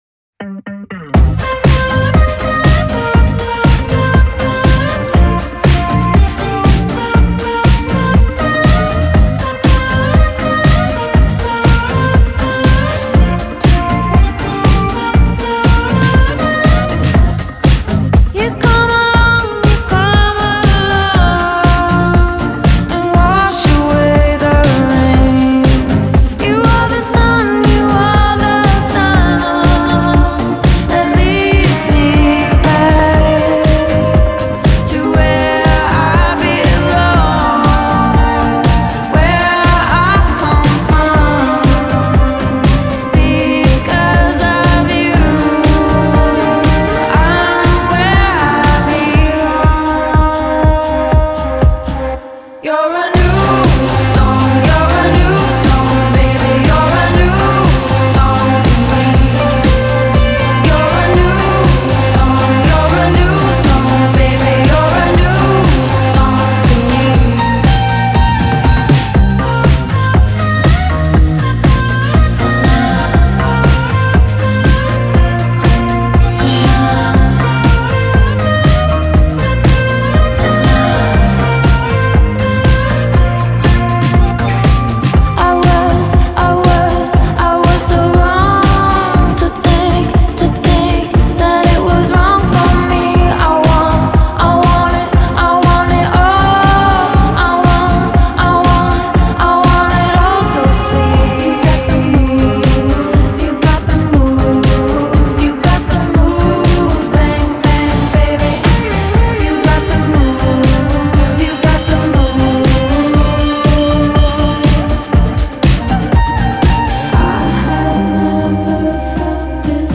dark trance rock